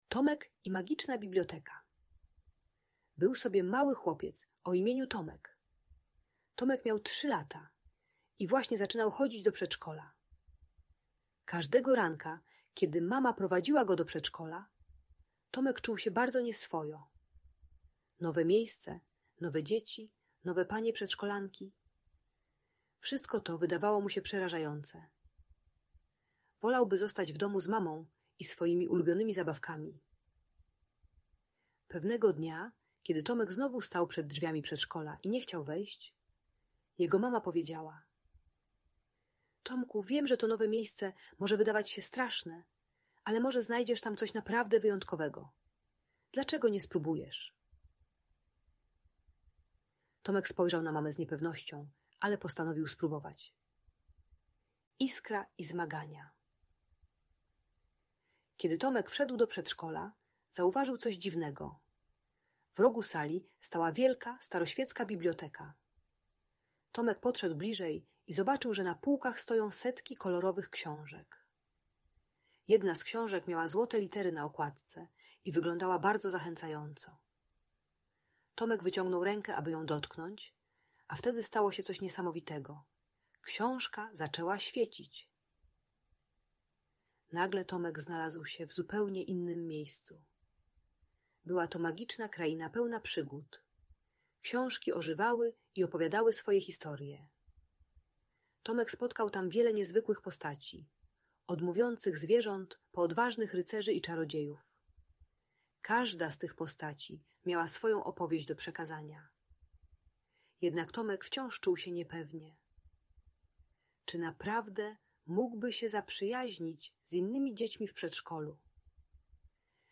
Tomek i Magiczna Biblioteka - Audiobajka dla dzieci